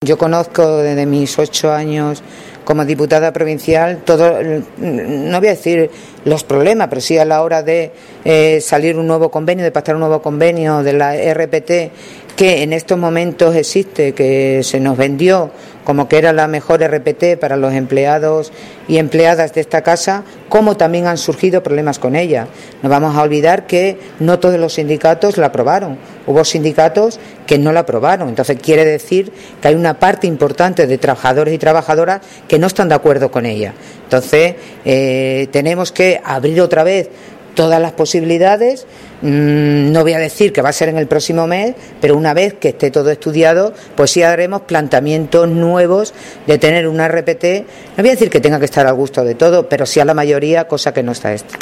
CORTES DE VOZ
En este primer encuentro, mantenido esta mañana en el Salón de Plenos de la Institución, Charo Cordero ha querido, en primer lugar, entregar su “lealtad y confianza” a los trabajadores, considerados por la presidenta como el motor fundamental para conseguir el funcionamiento y los objetivos marcados por el Gobierno.